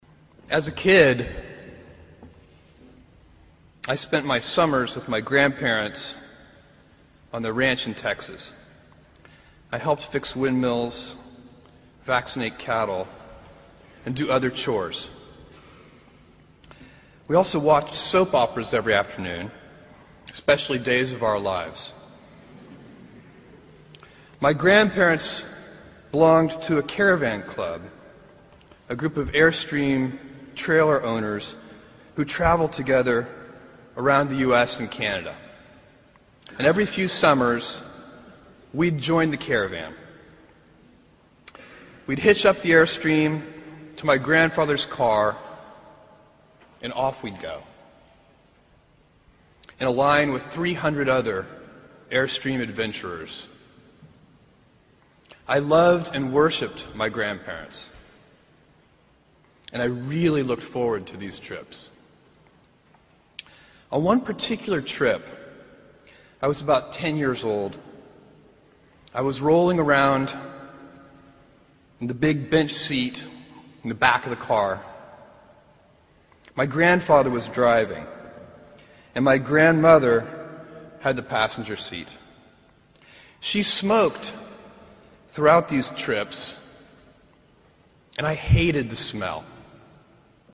在线英语听力室名人励志英语演讲 第109期:选择塑造人生(1)的听力文件下载,《名人励志英语演讲》收录了19篇英语演讲，演讲者来自政治、经济、文化等各个领域，分别为国家领袖、政治人物、商界精英、作家记者和娱乐名人，内容附带音频和中英双语字幕。